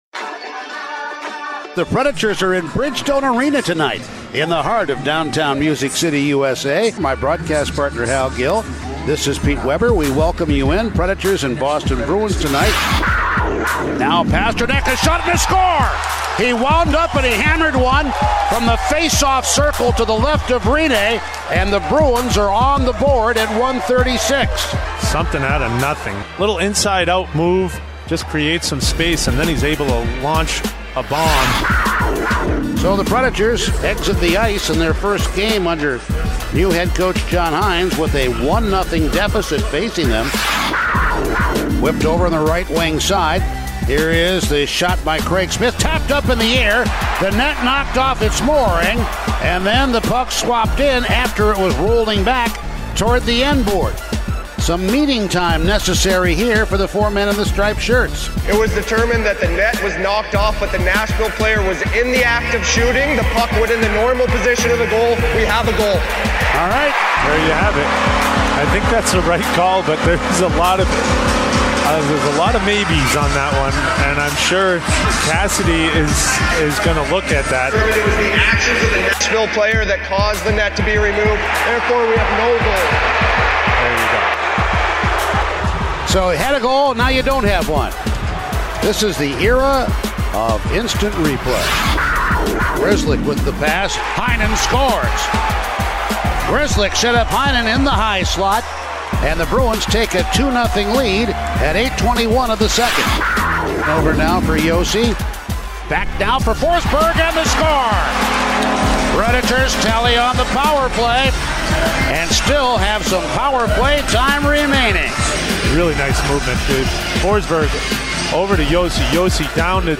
Full Radio Highlights of the Nashville Predators' 6-2 loss to Boston in new head coach John Hynes' debut as heard on the Nashville Predators Radio Network